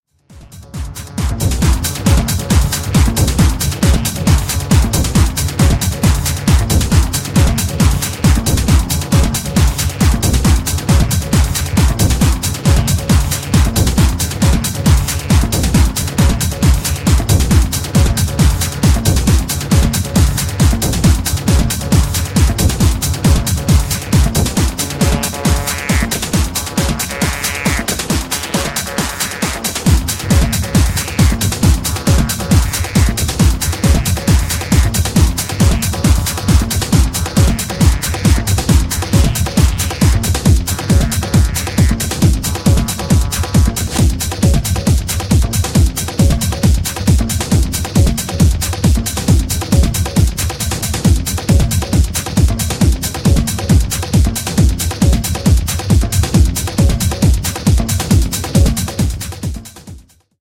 beaming the basement style to 21st century levels.